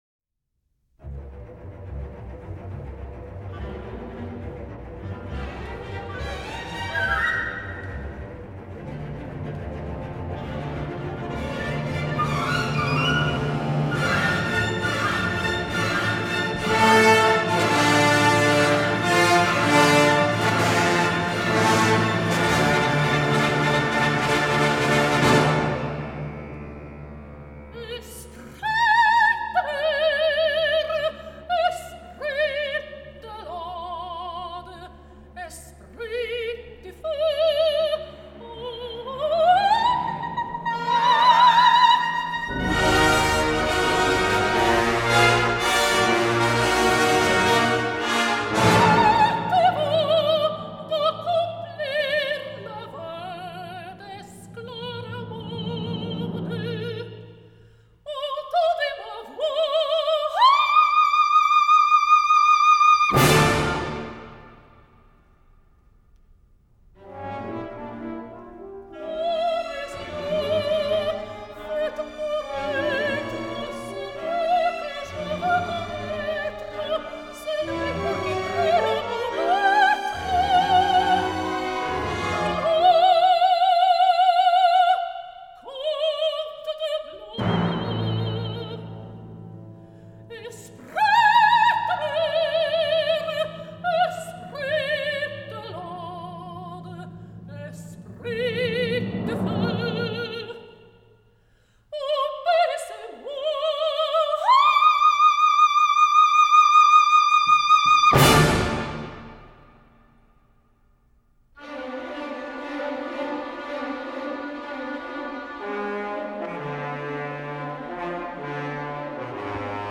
Джоан Сазерленд. Массне. Ария Эсклармонды из оперы "Эсклармонда"